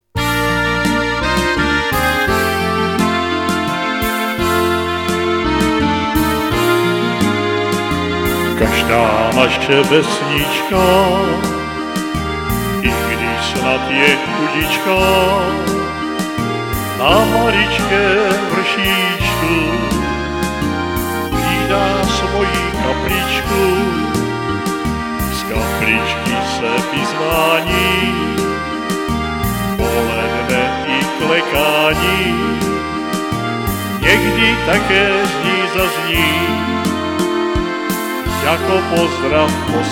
Rubrika: Národní, lidové, dechovka
- valčík